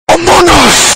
Amogus Screaming